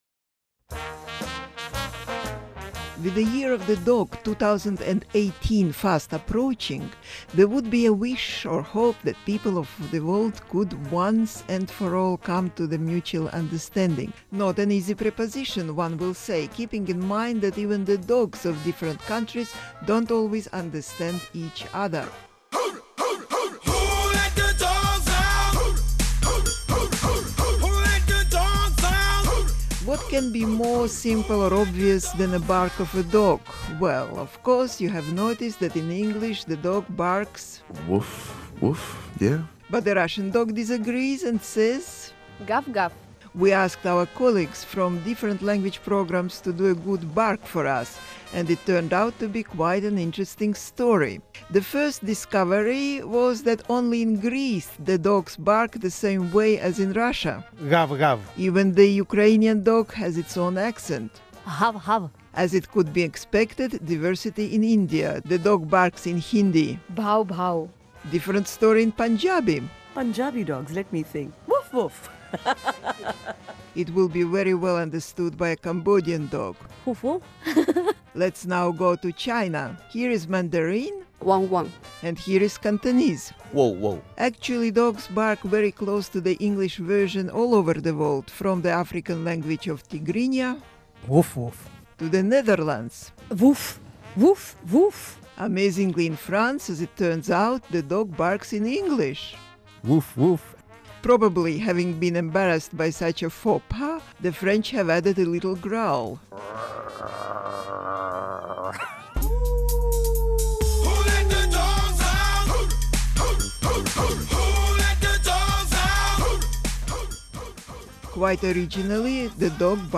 new_year_of_the_dog_2018_-with_a_little_bark.mp3